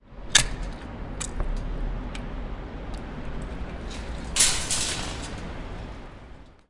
购物中心停车场 " 带上购物车
描述：在一个大的停车场里乘坐装有投币式锁定装置的购物车时发出的声音（嘈杂和混响的环境）. 用Zoom H4在Glories Shopping Mall的停车场里录制的。